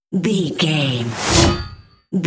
Whoosh electronic fast
Sound Effects
Atonal
Fast
futuristic
high tech
intense